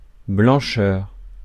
Ääntäminen
Ääntäminen France: IPA: /blɑ̃.ʃœʁ/ Haettu sana löytyi näillä lähdekielillä: ranska Käännös Substantiivit 1. белота 2. белина {f} Suku: f .